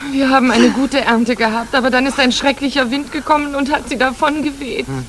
Als ich davon las, recherchierte ich ein wenig und war hellauf begeistert, als ich auf Youtube eine alte Fernseh-Aufzeichnung fand, die dort jemand vor 3 Jahren online stellte.